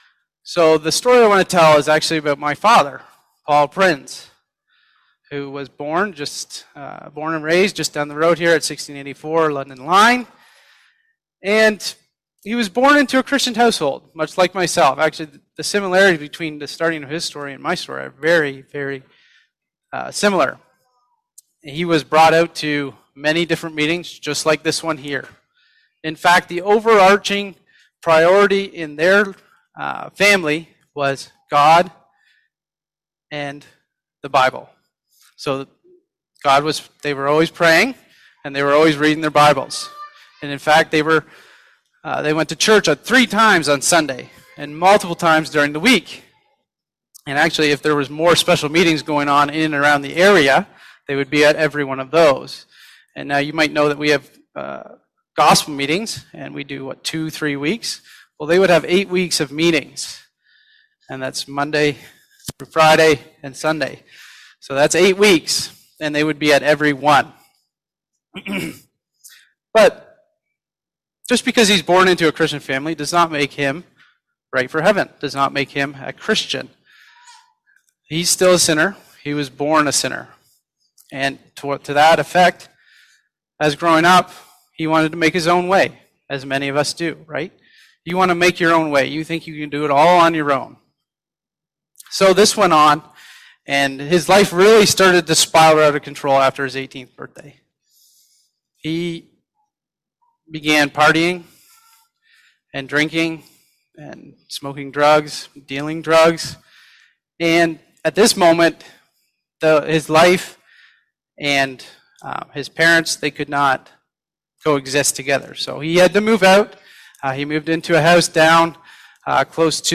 (Recorded in Sarnia Gospel Hall, ON, Canada, 8th Mar 2025)
Testimonies